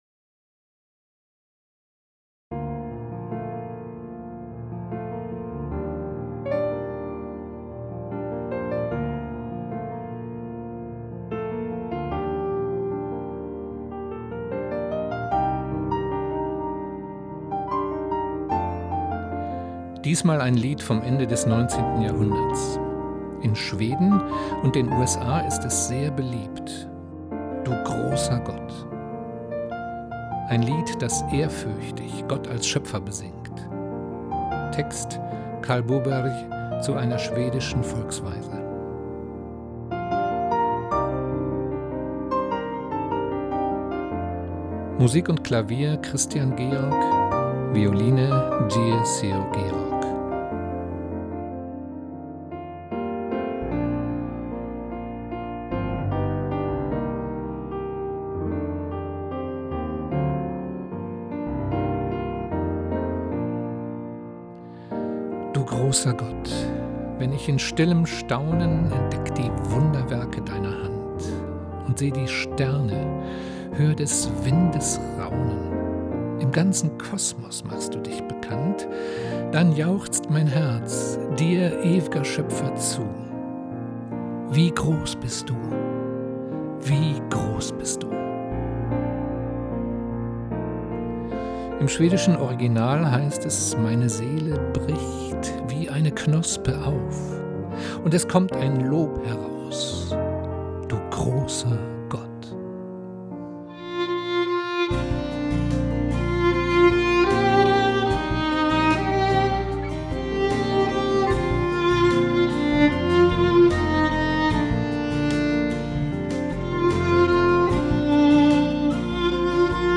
[Zum Hören auf » weiterlesen « oder aufs »Bild« klicken, dort auf die » mp3 «-Datei] »Du großer Gott« — ein romantisches Lied aus Schweden (Ende des 19.
Piano & Arrangement
Geige